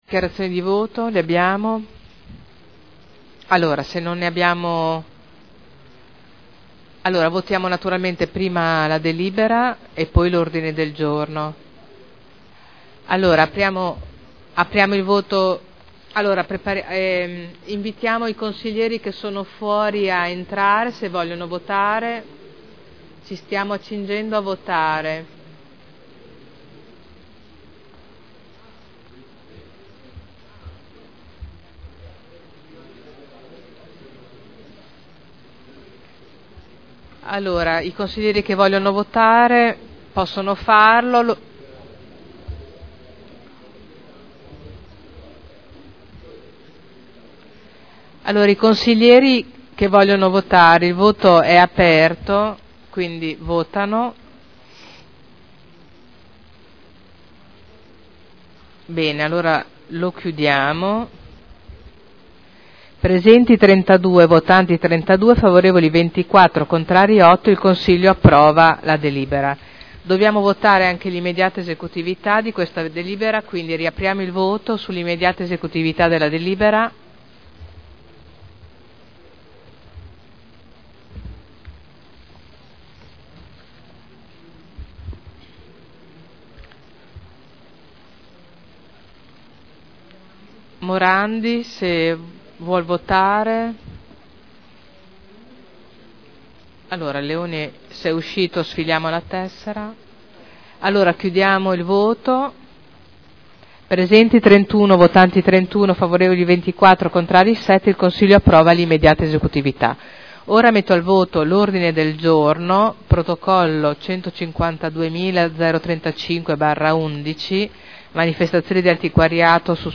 Seduta del 22/12/2011. Mette ai voti nuovo ordine del giorno e proposta di deliberazione.